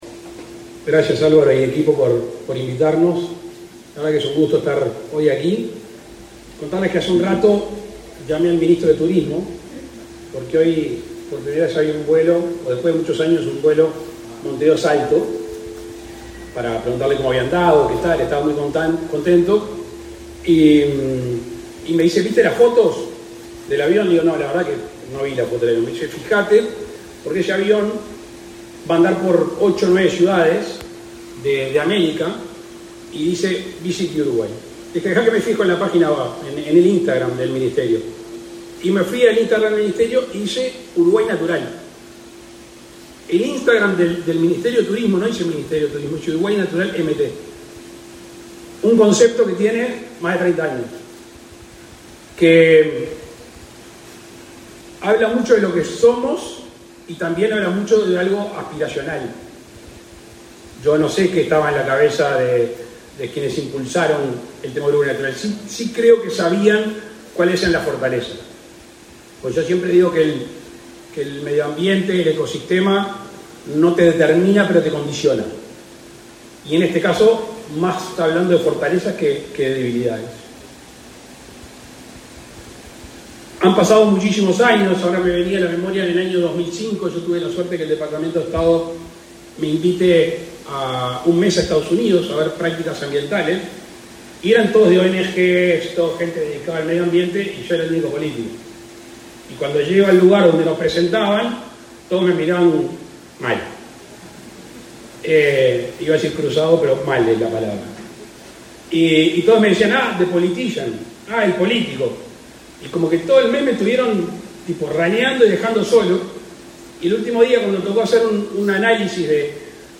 Palabras del presidente de la República, Luis Lacalle Pou
Palabras del presidente de la República, Luis Lacalle Pou 08/10/2024 Compartir Facebook X Copiar enlace WhatsApp LinkedIn El presidente de la República, Luis Lacalle Pou, participó, este 8 de octubre, en la inauguración de las instalaciones de la planta industrial de la empresa Envases, en Pando.